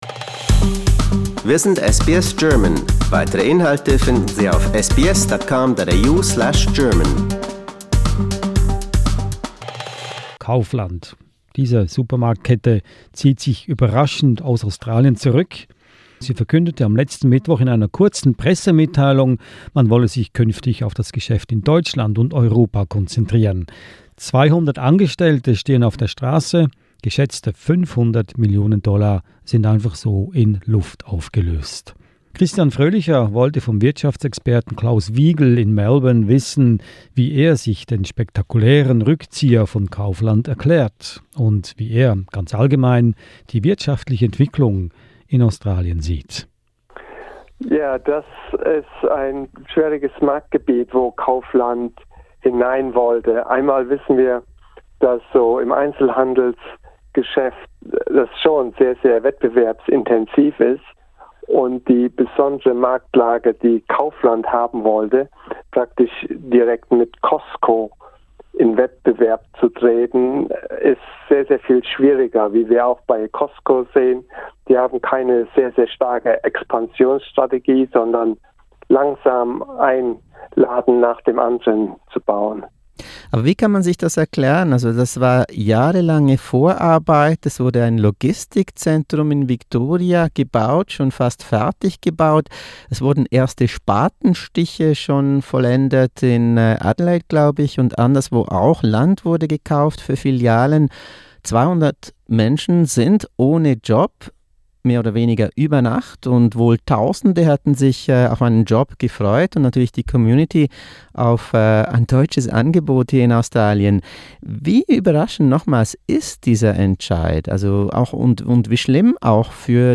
Kaufland abandons Australia, but why? An interview